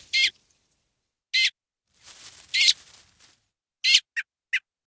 dc0f4c9042 Divergent / mods / Soundscape Overhaul / gamedata / sounds / ambient / soundscape / swamp / sfx_4.ogg 122 KiB (Stored with Git LFS) Raw History